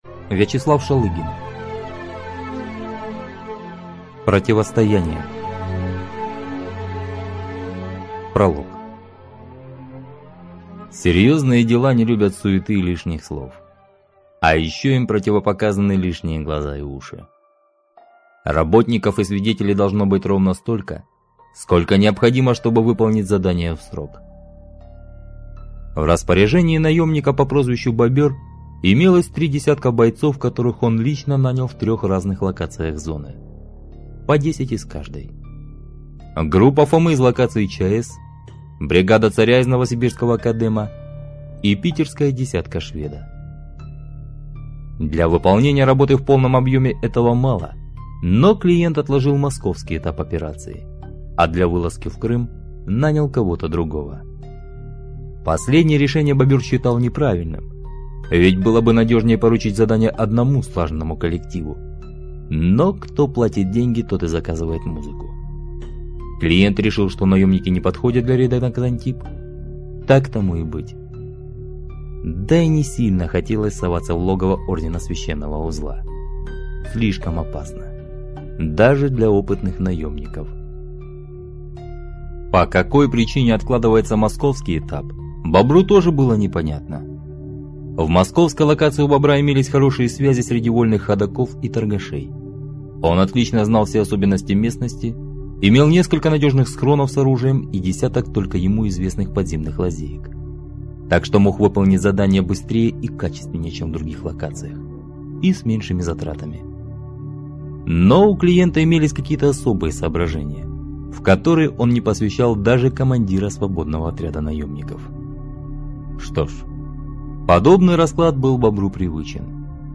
Aудиокнига Противостояние